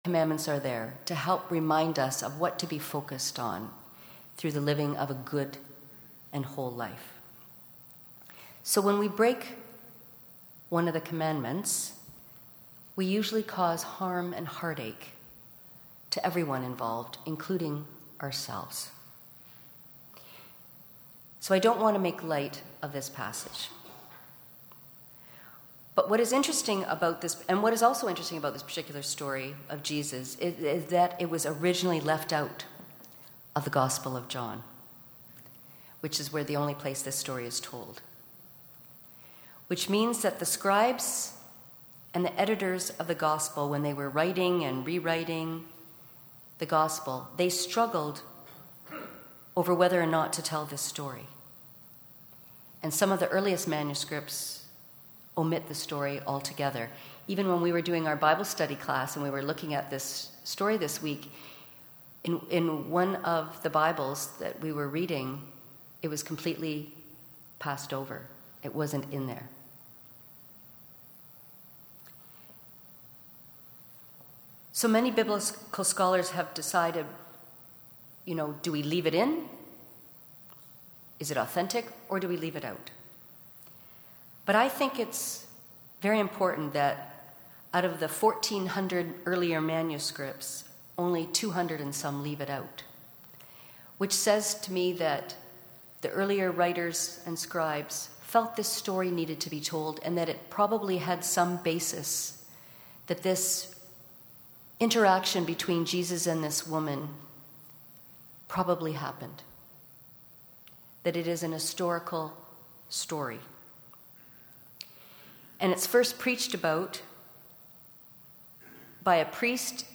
Sermon
Guest Speaker